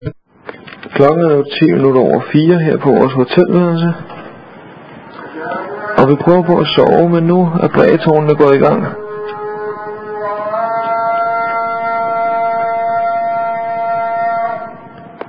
Klokken er kun 04.10 da en frygtelig støj vækker os!!!!! Det er dagens første bøn, der skråles ud over byen fra de høje brægetårne!